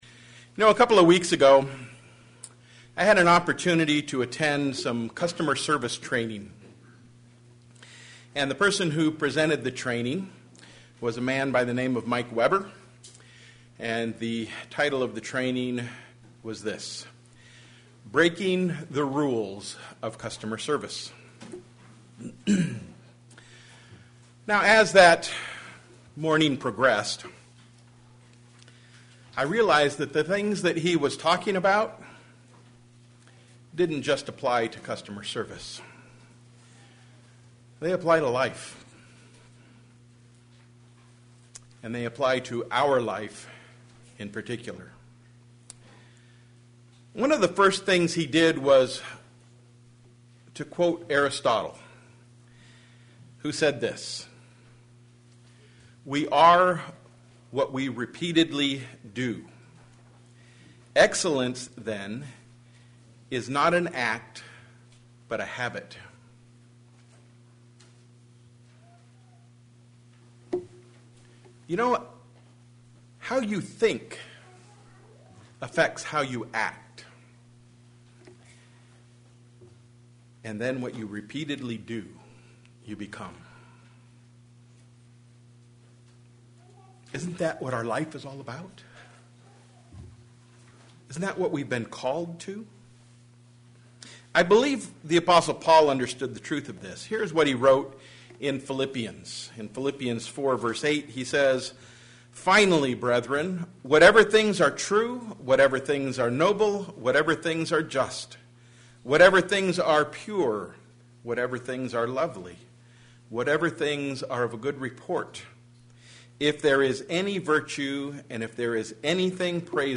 It got me to thinking about how we are called to develop good customer service as Christians. sermon Studying the bible?